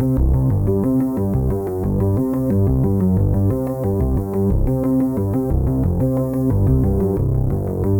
Index of /musicradar/dystopian-drone-samples/Droney Arps/90bpm
DD_DroneyArp2_90-C.wav